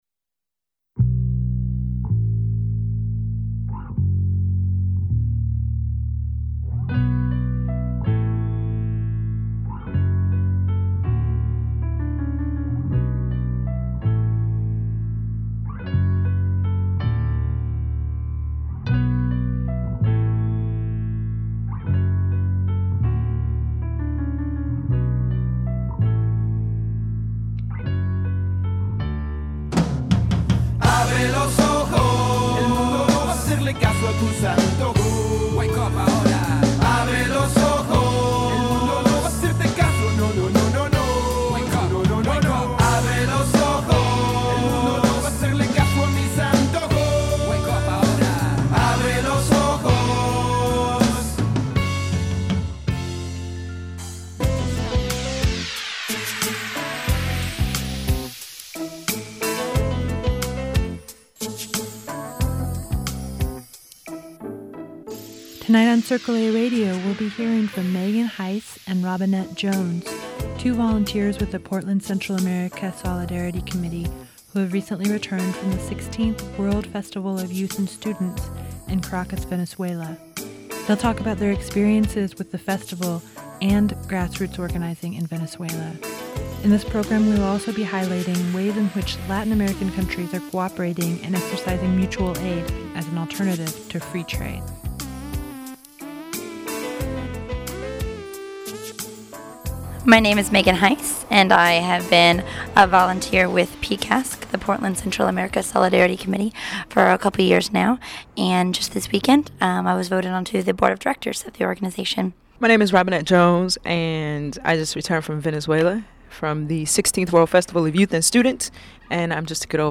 Venezuela - interview with PCASC